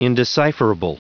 Prononciation du mot indecipherable en anglais (fichier audio)
Prononciation du mot : indecipherable